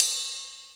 D2 RIDE-08-L.wav